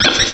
Add all new cries
cry_not_petilil.aif